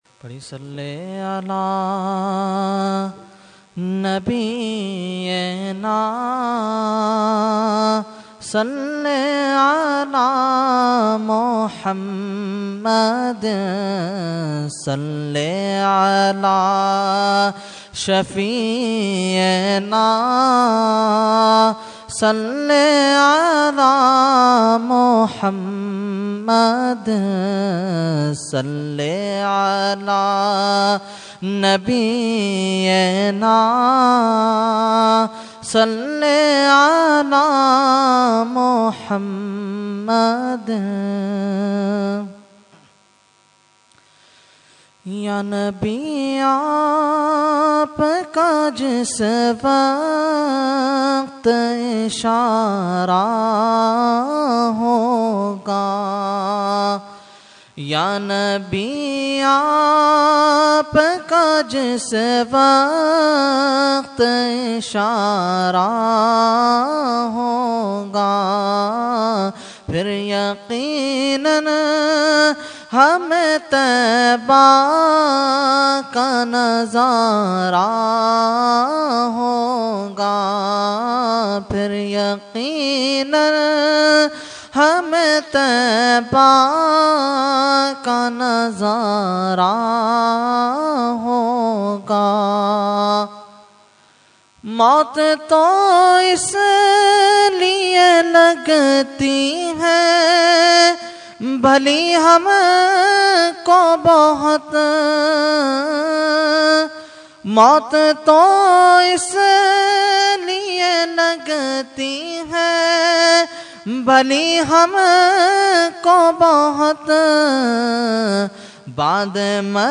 Category : Naat | Language : UrduEvent : 11veen Shareef 2014